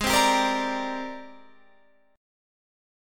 AbM#11 chord